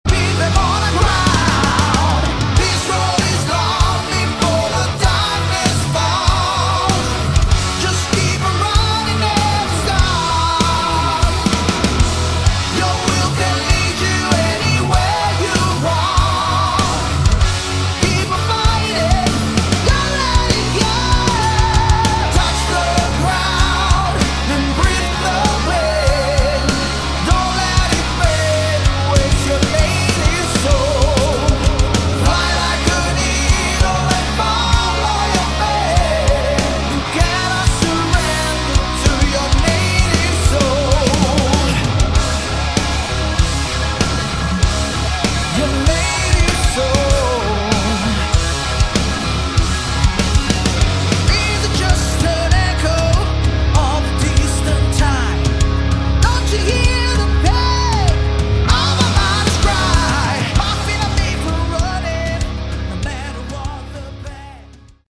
Category: Hard Rock
vocals, keyboards
guitar
bass
drums